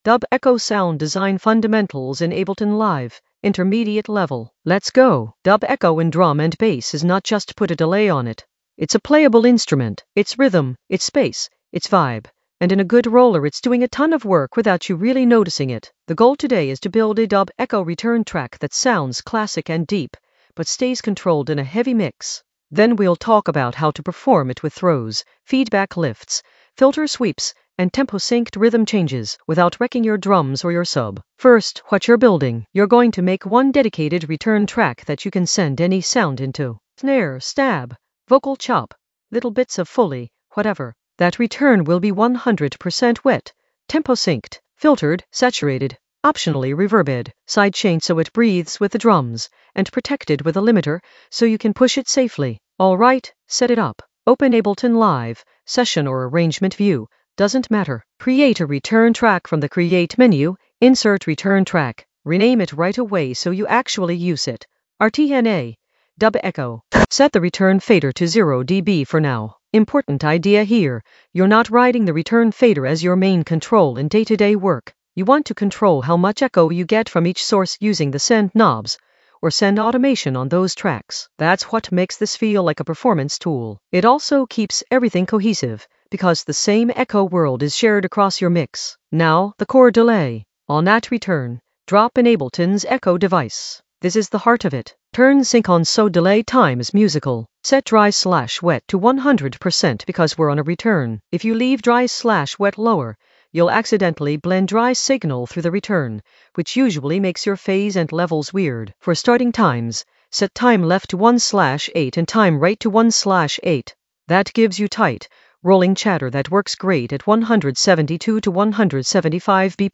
Narrated lesson audio
The voice track includes the tutorial plus extra teacher commentary.
An AI-generated intermediate Ableton lesson focused on Dub echo sound design fundamentals in the Sound Design area of drum and bass production.